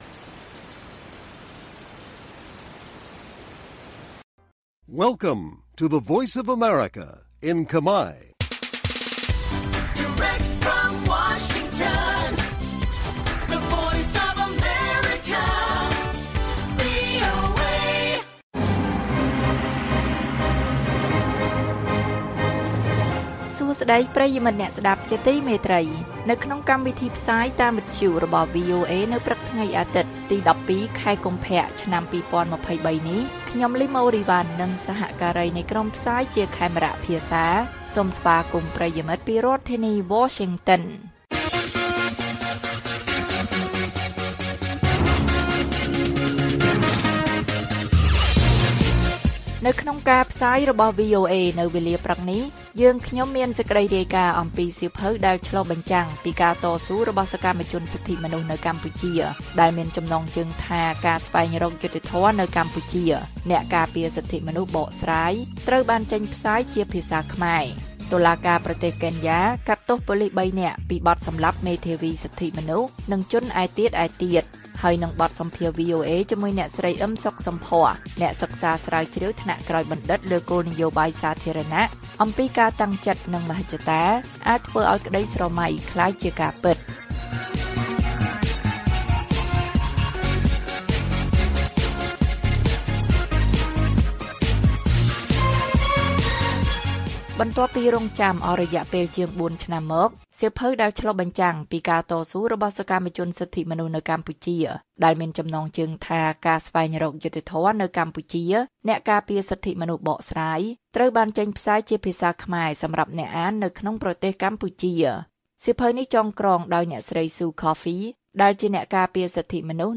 ព័ត៌មានពេលព្រឹក ១២ កុម្ភៈ៖ សៀវភៅ«ការស្វែងរកយុត្តិធម៌នៅកម្ពុជា»ចេញផ្សាយជាភាសាខ្មែរ